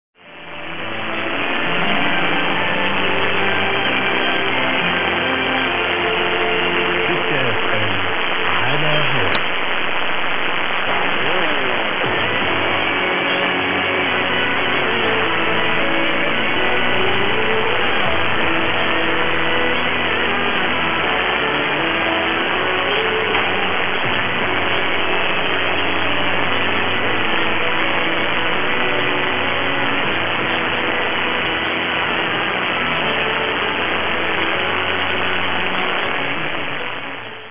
Now that UK had left the channel, I could hear this new one on Jan 2024.